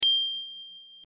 We'll make an annoying bell that rings every second.
our bell sound.
quickstart-bell.mp3